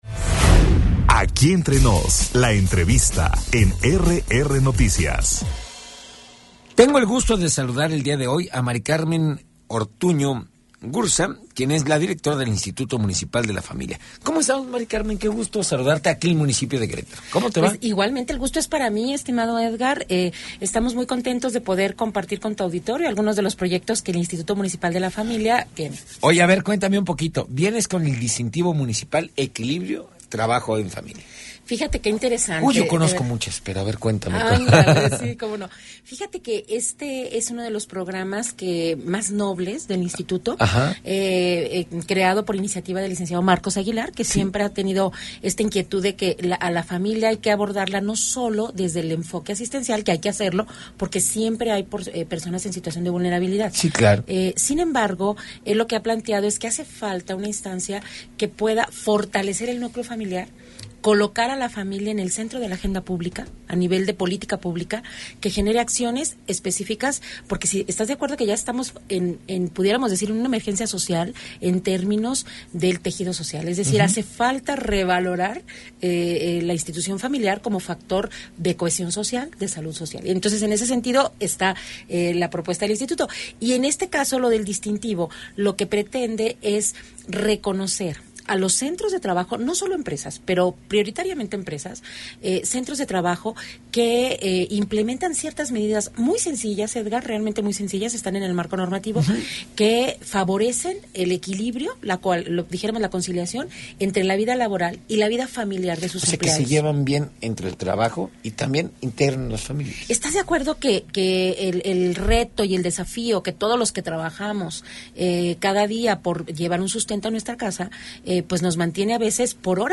Entrevista con Ma. del Carmen Ortuño Gurza Dir. del Instituto Municipal de la Familia - RR Noticias